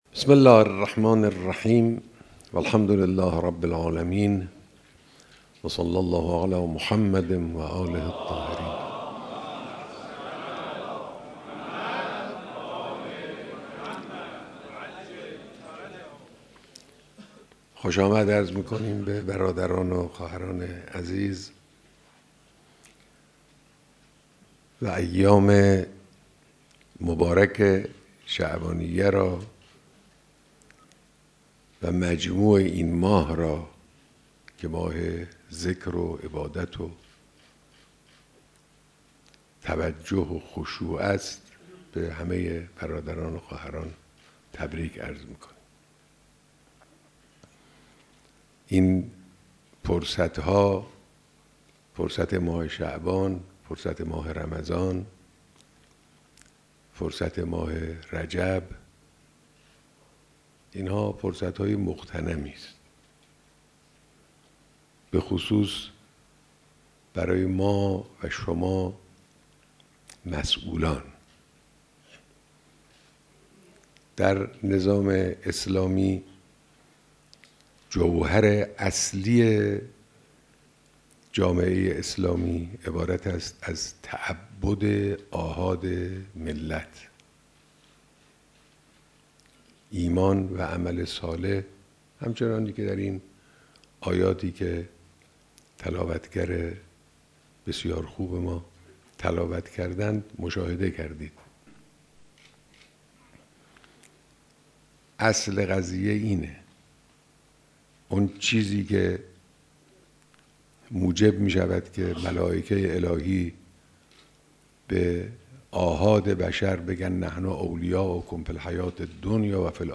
بیانات در دیدار رئیس و نمایندگان مجلس شورای اسلامی